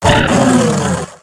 Audio / SE / Cries / ZWEILOUS.ogg